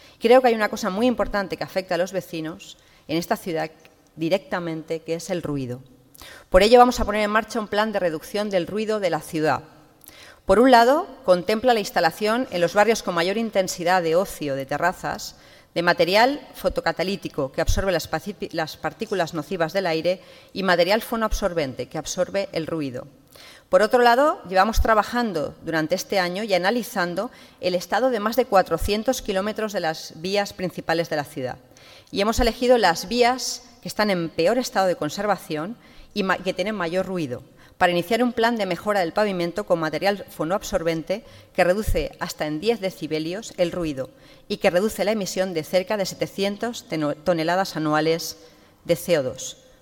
La alcaldesa María José Catalá ha terminado su discurso en el Debate del Estado de la Ciudad con un asunto que ha considerado “capital para el municipalismo”, la reforma de la financiación local